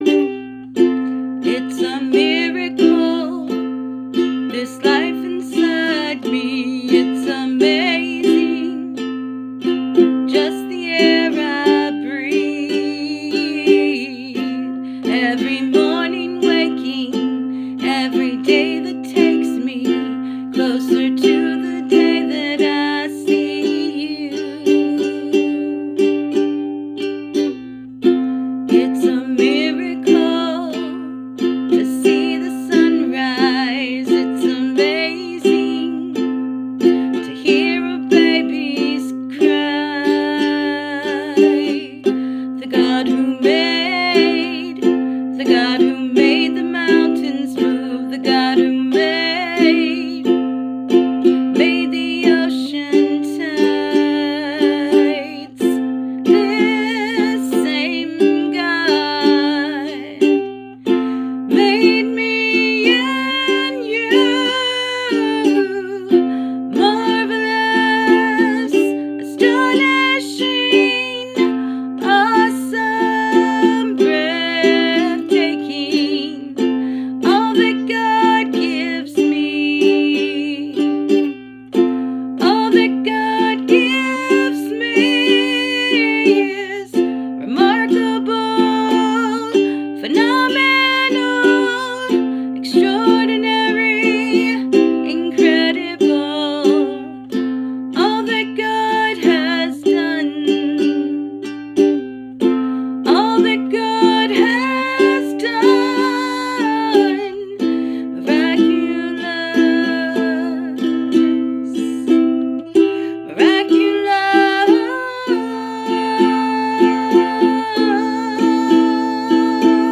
Very pretty and uplifting!
I enjoyed hearing this lyric sung straight from the heart. Your voice is lovely and your artistry shines!